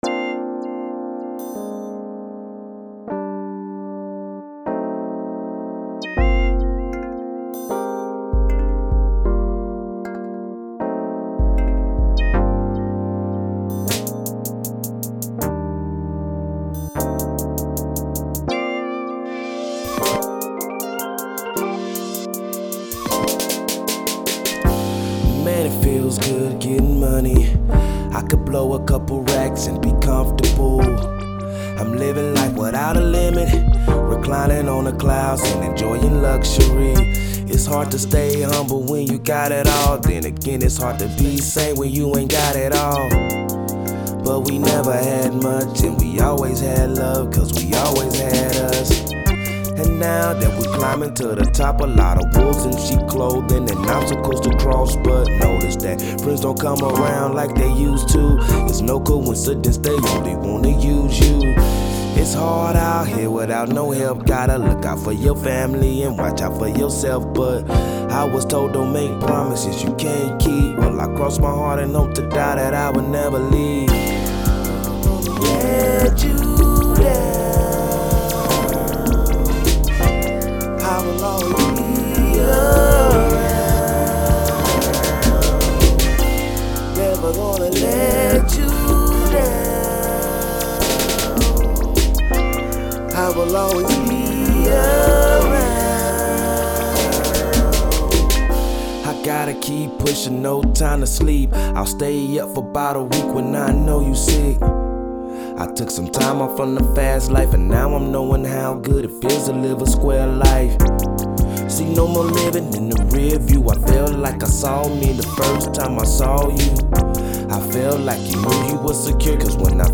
Singer, Songwriter, and Producer.